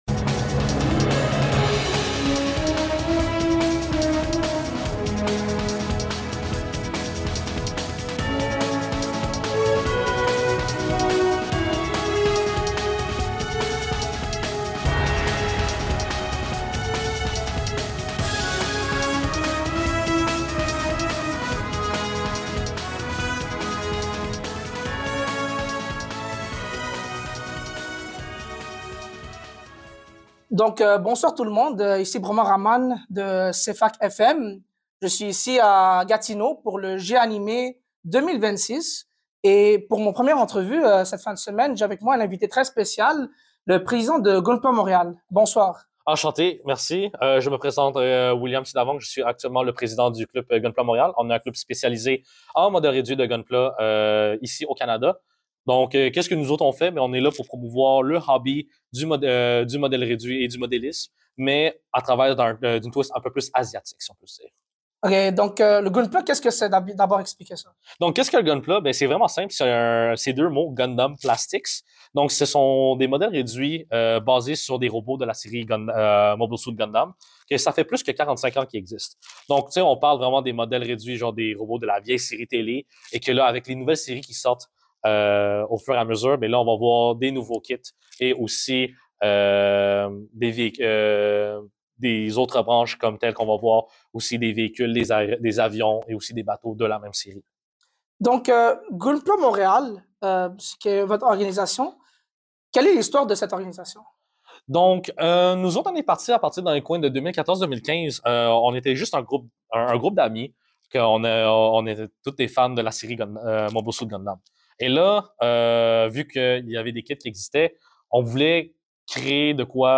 CFAK à G-Anime